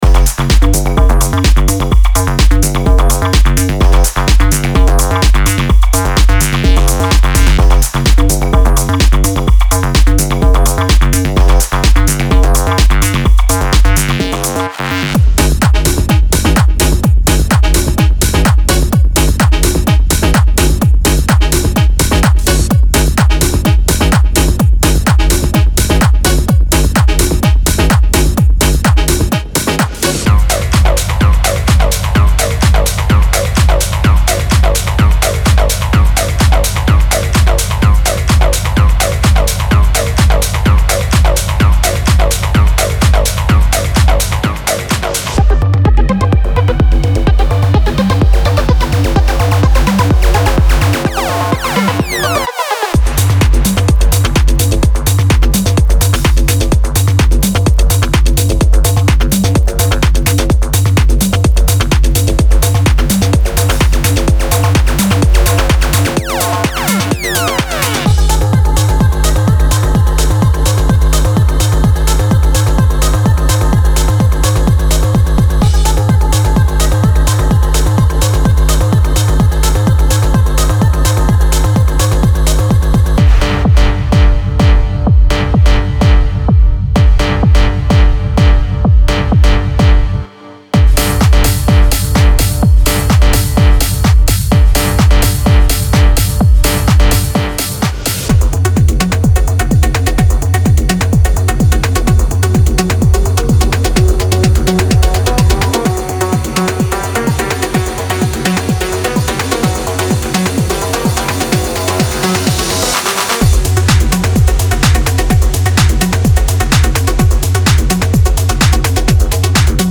Melodic Techno Tech House Techno
This pack includes powerful rhythmic loops, rich basslines and unique sounds that will add depth and atmosphere to your compositions.